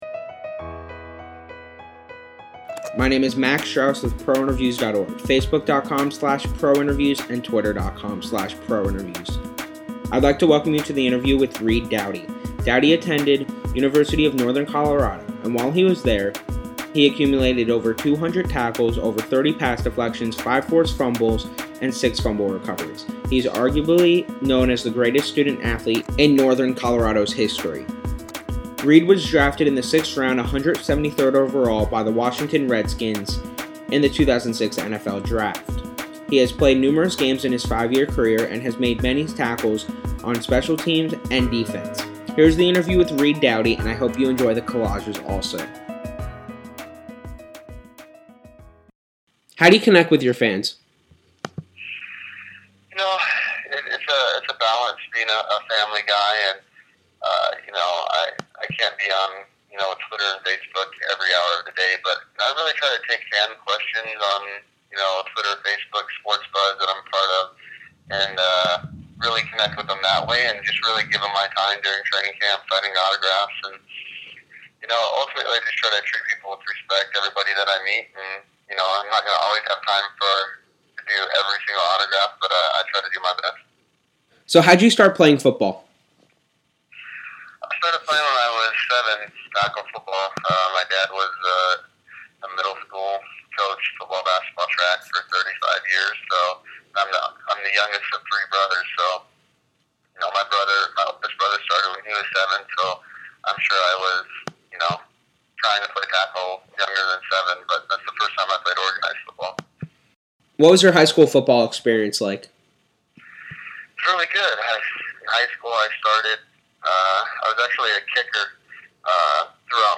Redskins DB, Reed Doughty Interview
He was a teammate of Sean Taylor and shares his memories with him. Check out our interview that was conducted before the 2011 NFL season.
interview-with-reed-doughty.mp3